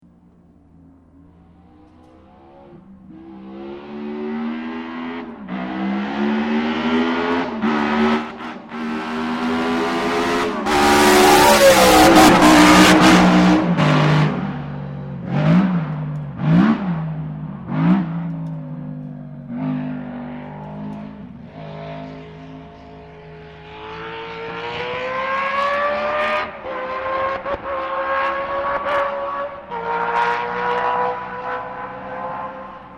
BRM geluid, KIPPENVEL!
BRM-loudpass.mp3